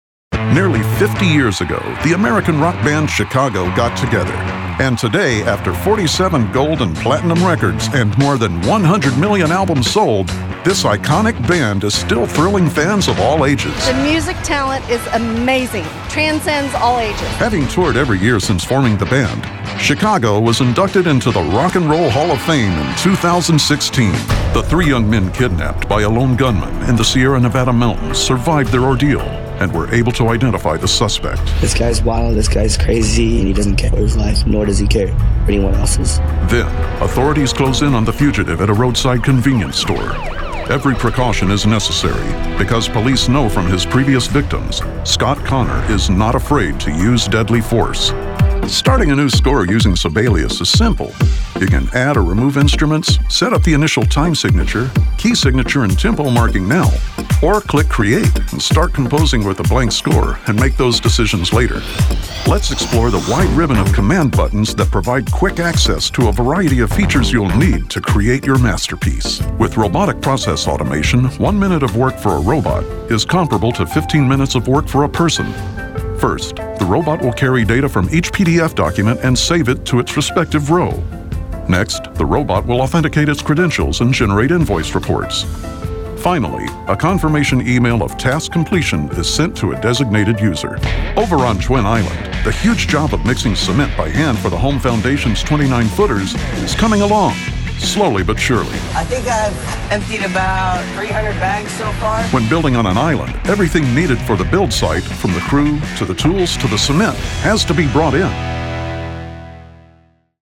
Southern, Western
Middle Aged